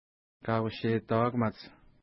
Kaushetauakamat Next name Previous name Image Not Available ID: 136 Longitude: -62.3354 Latitude: 55.6823 Pronunciation: ka:u:ʃeta:wa:kəma:ts Translation: Esker Lake Feature: lake Explanation: There is an esker nearby.